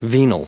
added pronounciation and merriam webster audio
727_venality.ogg